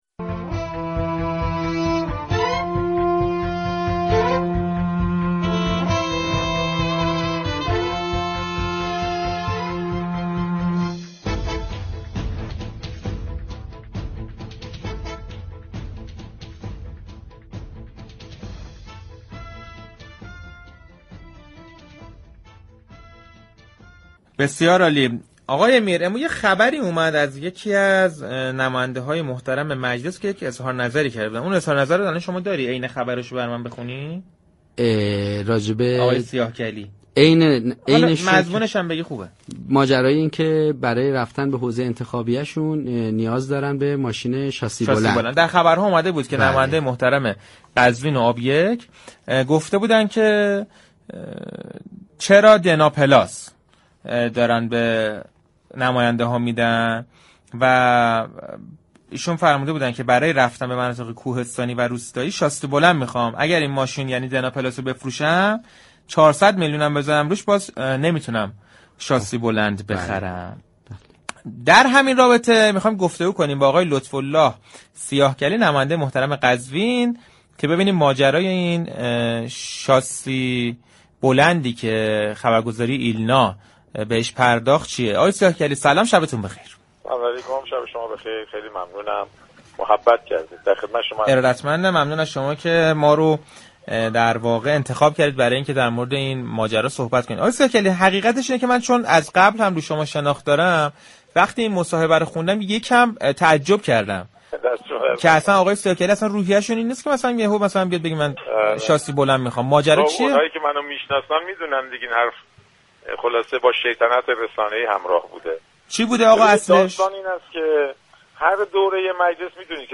لطف الله سیاهكلی، نماینده مردم قزوین و آبیك در مجلس شورای اسلامی در گفتگو با